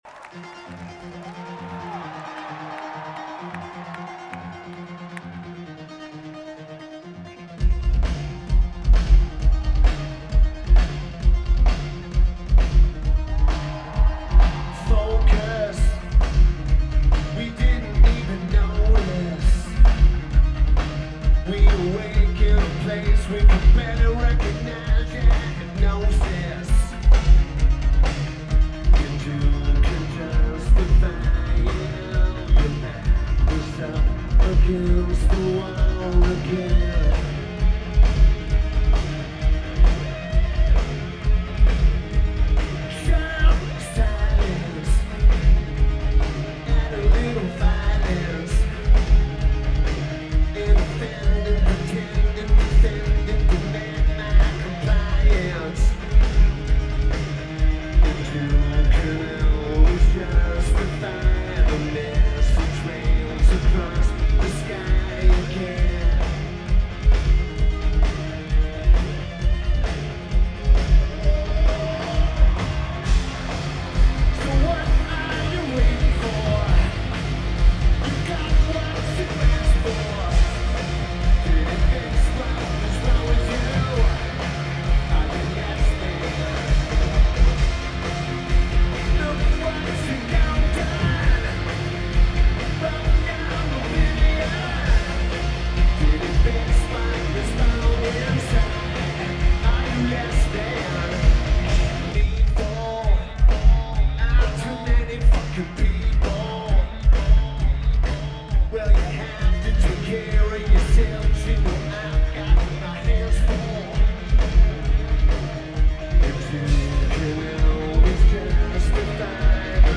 Greek Theatre
A very good tape!